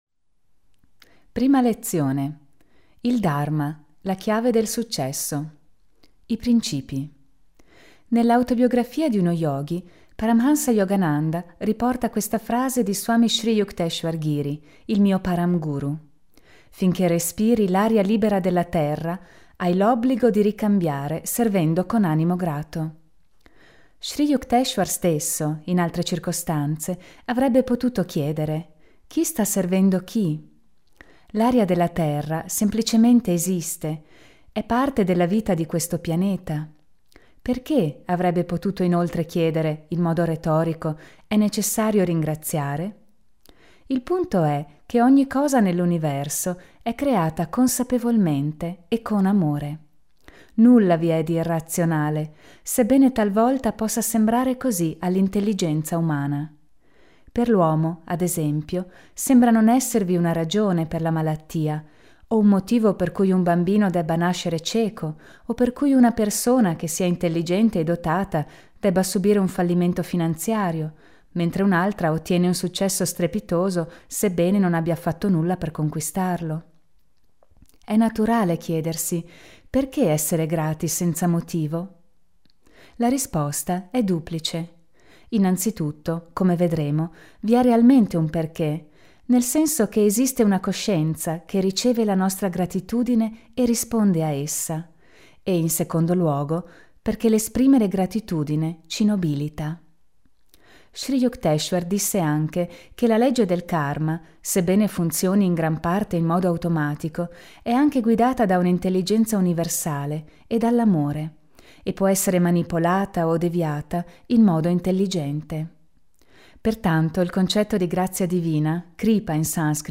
La via del successo con i princìpi dello yoga – audiolibro scaricabile
La lettura dell’audiolibro non corrisponde perfettamente con il testo del libro cartaceo perchè basata su una diversa traduzione.
Voci